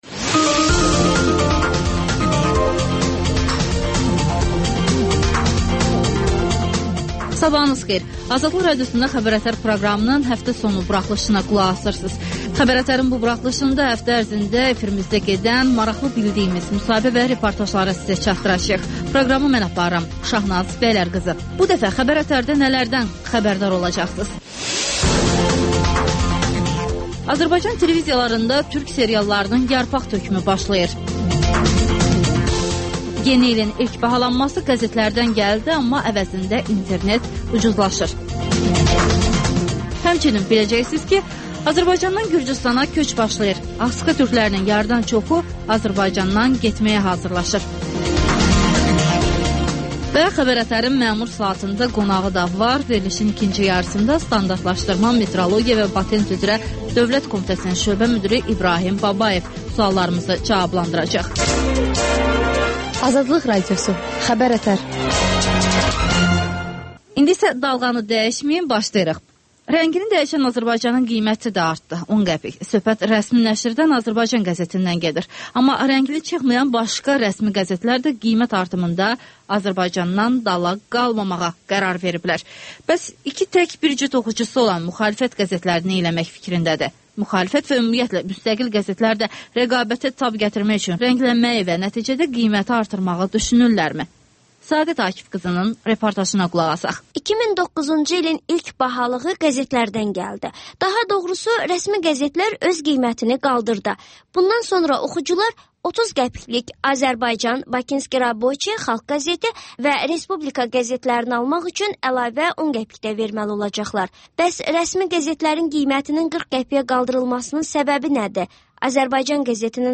Xəbərlər, müsahibələr (Şənbə buraxılışı)